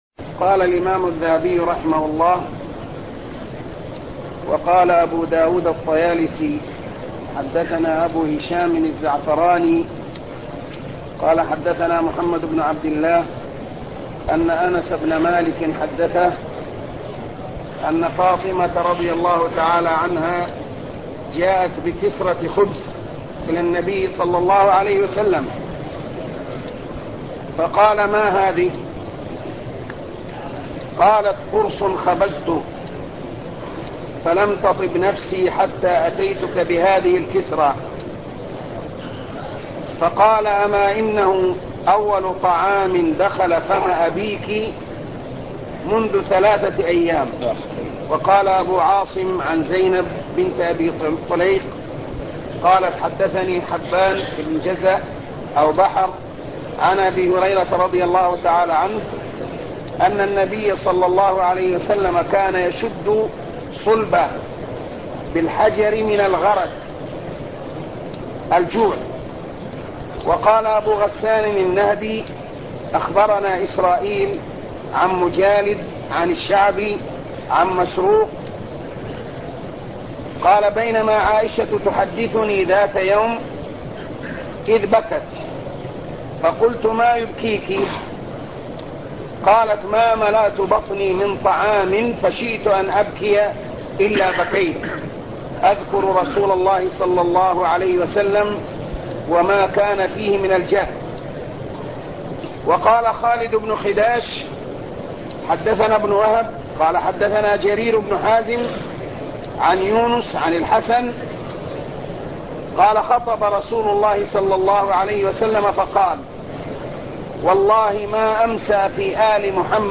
شرح السيرة النبوية الدرس 58